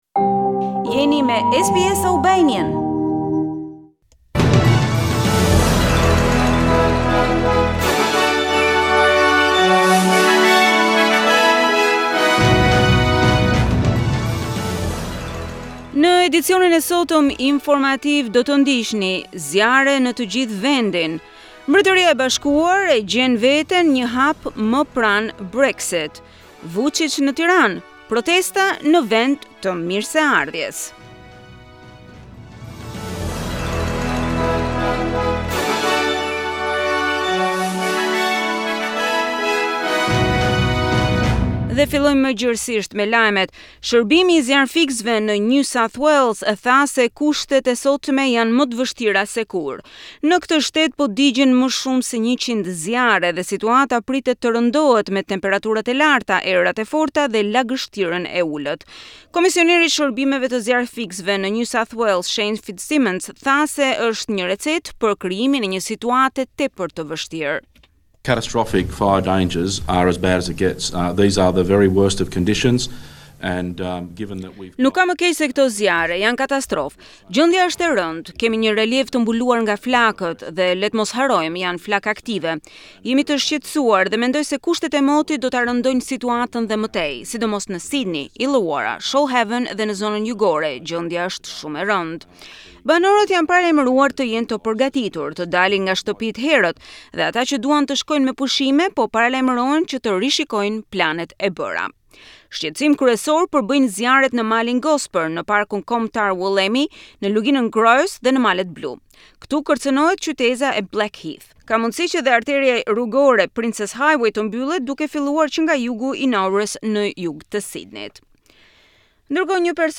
News Bulletin - 21 December 2019